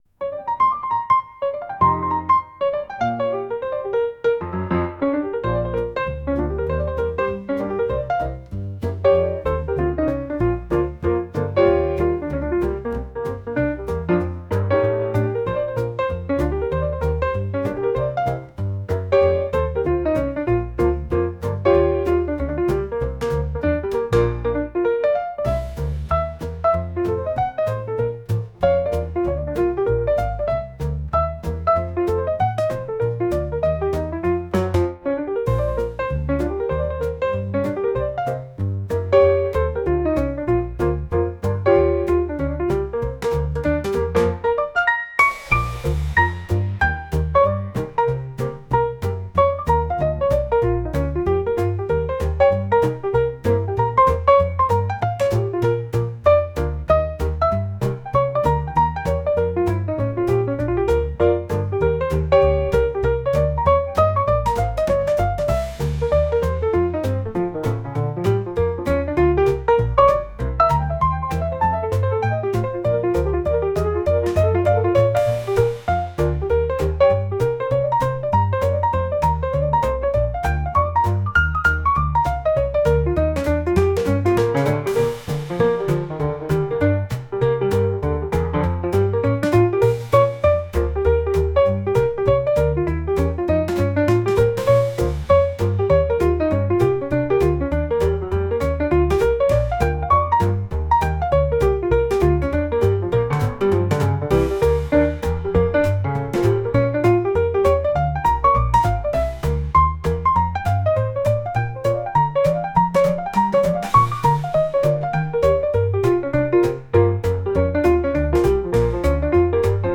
ジャンルJAZZ
楽曲イメージワクワク, 幸せ, 日常, 楽しい
シーン店舗BGM